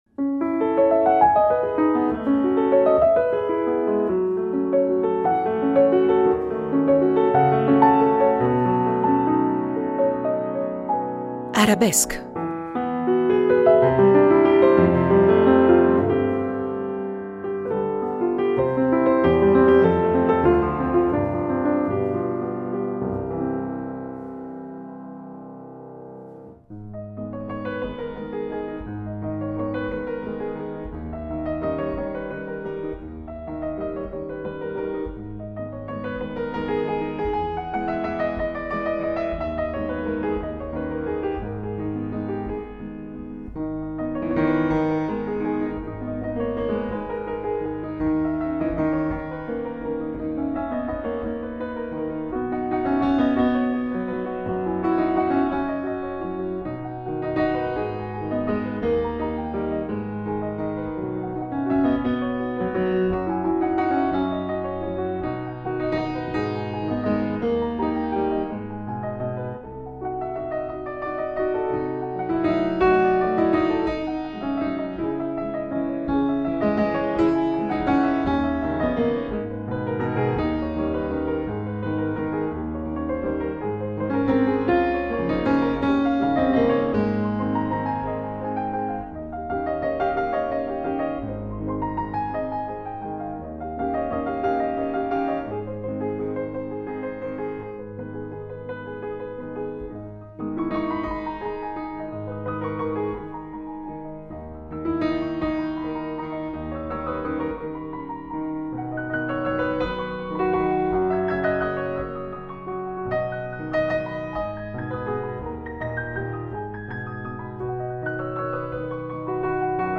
Prima parte, incontro
duo pianistico